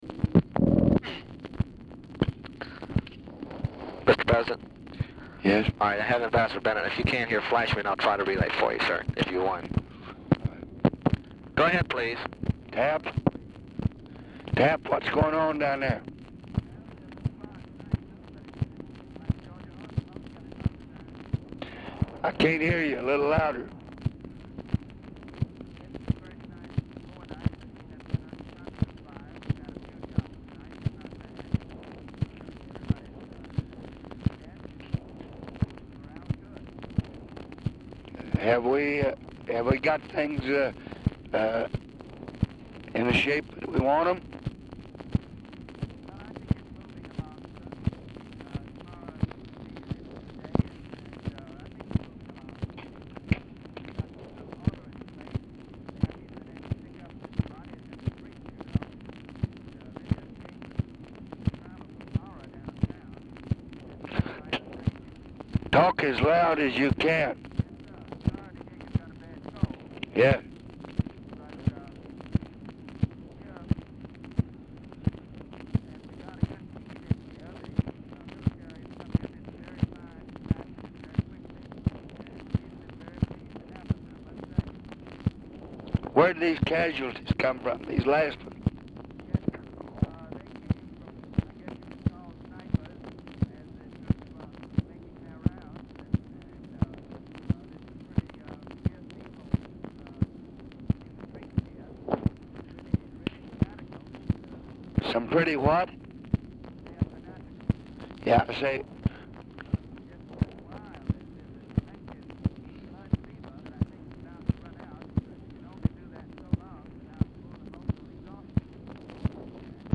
RECORDING ENDS BEFORE CONVERSATION IS OVER; BENNETT IS ALMOST INAUDIBLE
Format Dictation belt
Specific Item Type Telephone conversation